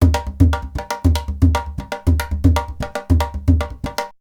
PERC 24.AI.wav